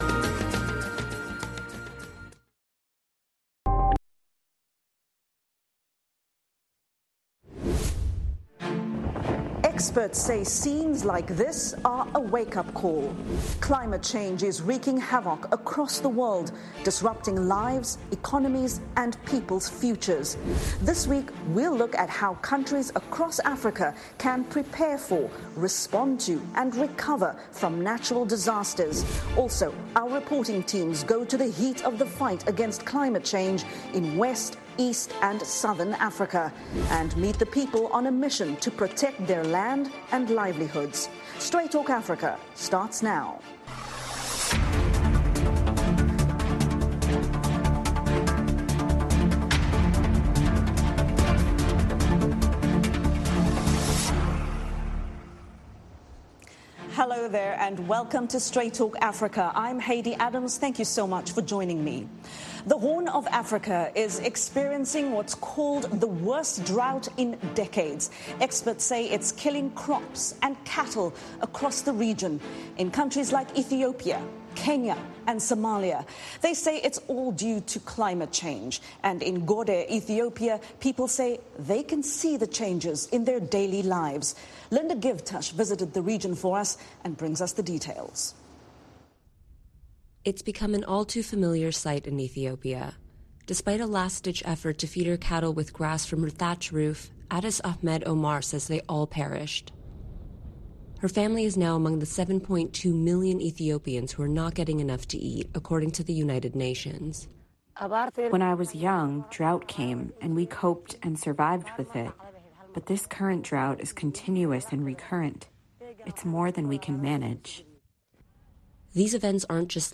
Straight Talk Africa [simulcast] Wed.,